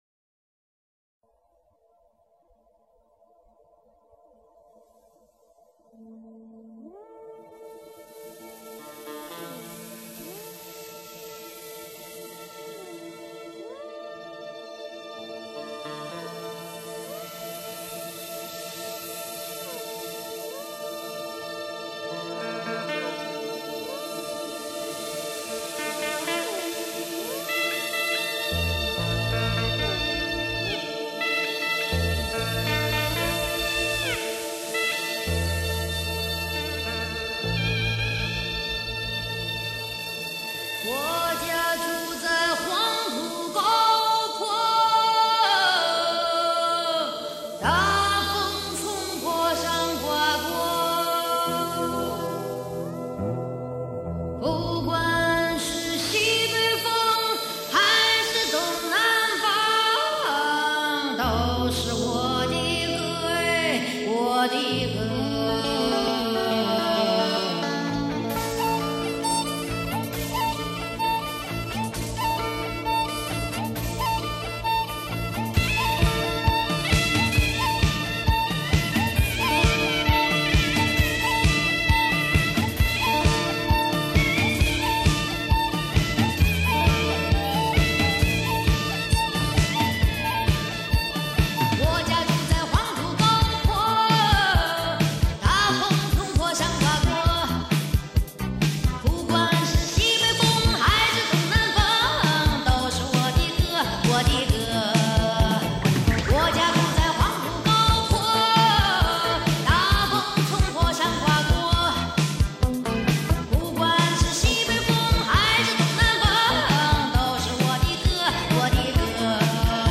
西北风